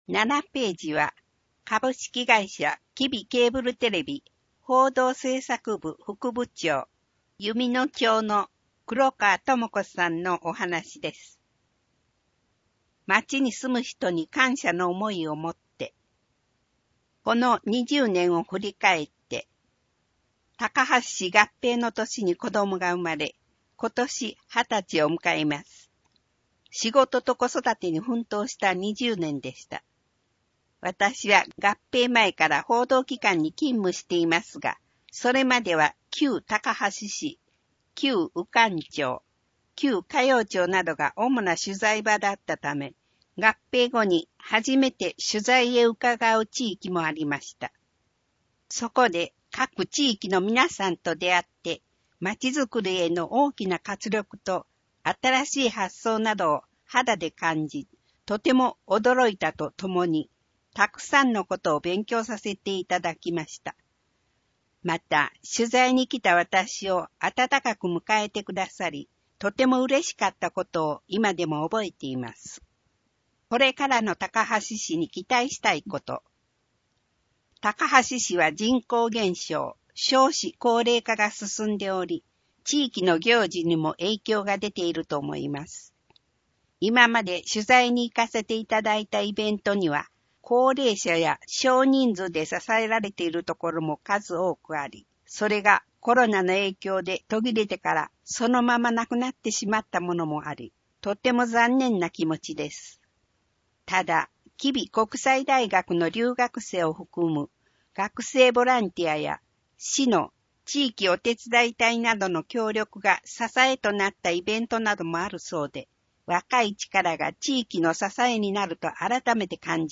声の広報　広報たかはし10月号（240）